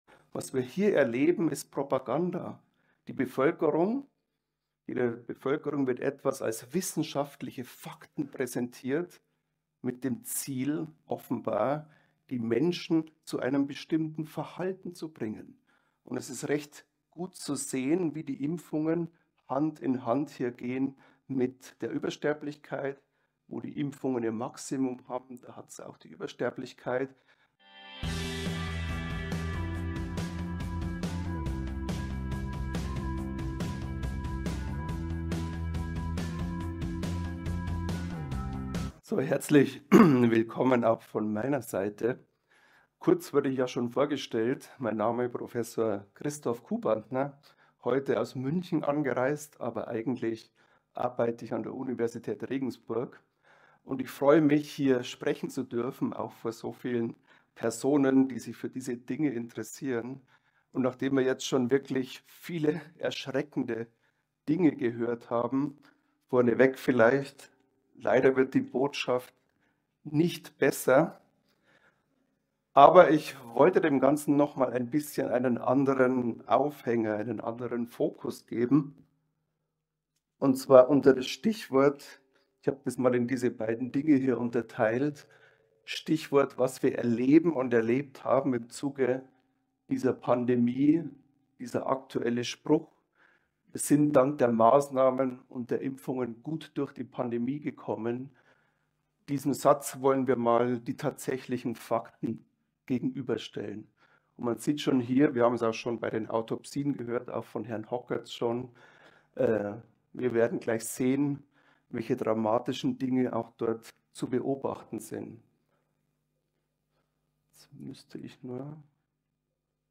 In diesem Vortrag vergleicht er die Propaganda bei Corona mit den tatsächlichen Fakten und schaut sich besonders die Übersterblichkeit an.